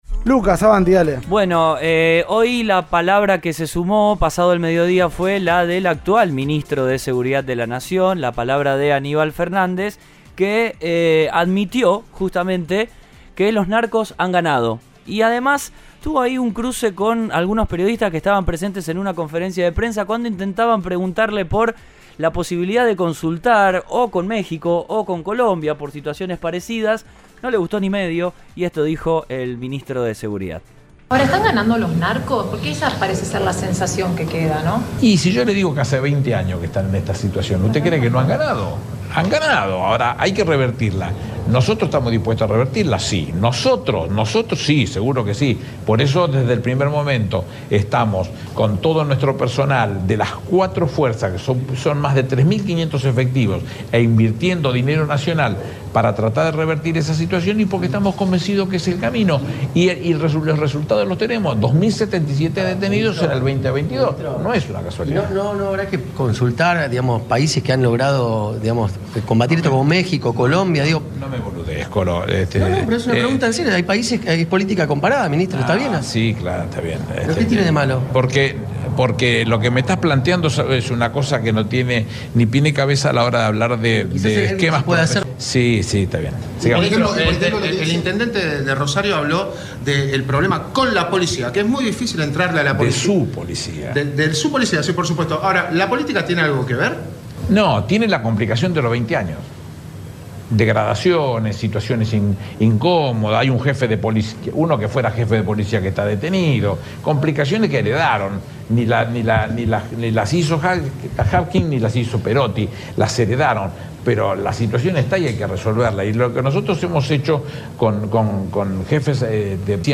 La presidenta del Pro y ex ministra de Seguridad de la Nación habló en Cadena 3 Rosario luego del ataque a un supermercado ligado a la familia Messi y los dichos del funcionario al respecto.